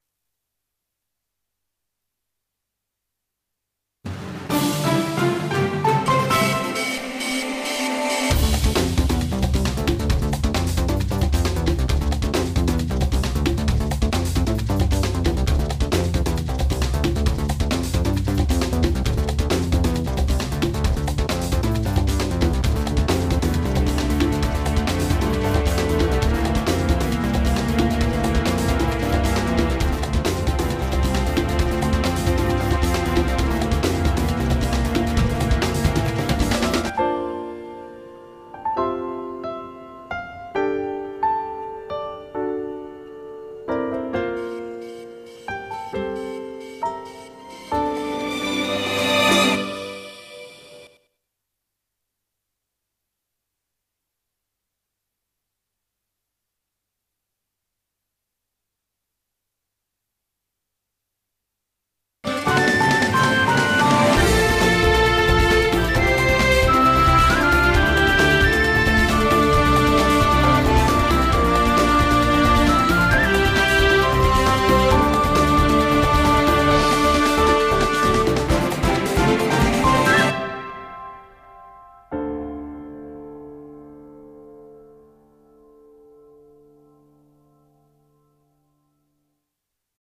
CM風声劇「華那ノ國」